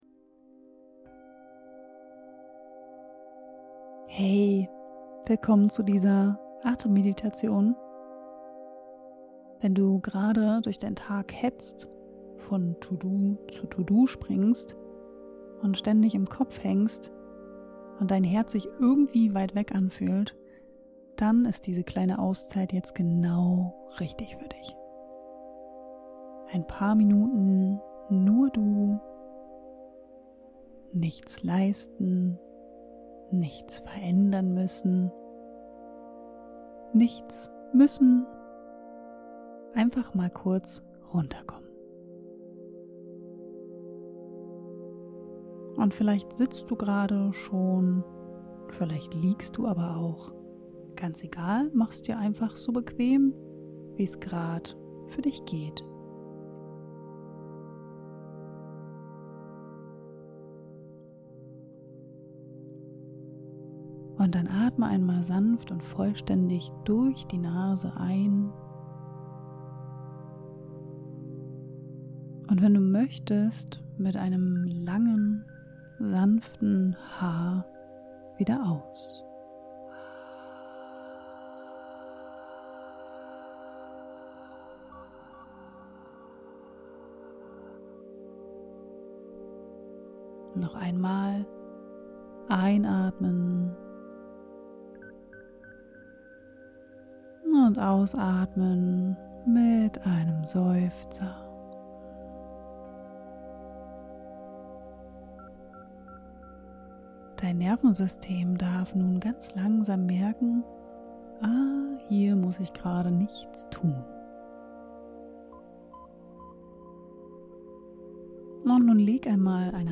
Atemmeditation - Wenn dir einfach alles zu viel ist - Kleine Auszeit nur für dich